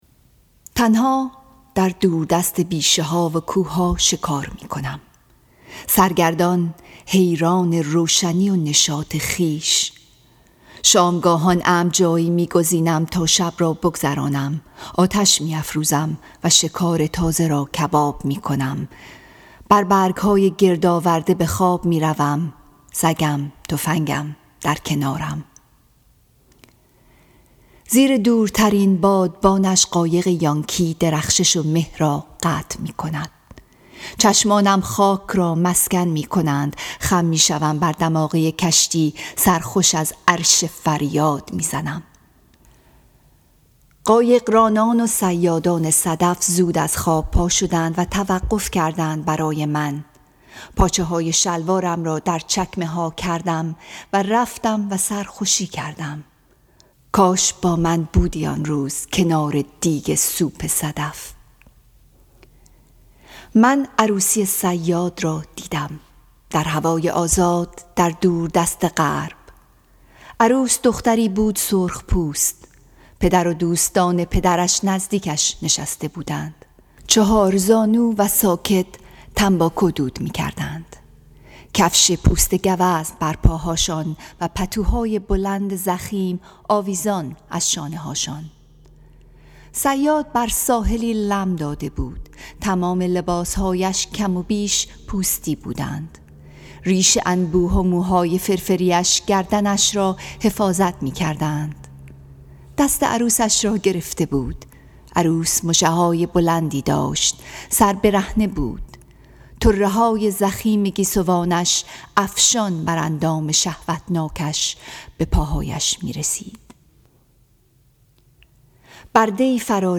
Song of Myself, Section 10 —poem read
SOM_10_persian.mp3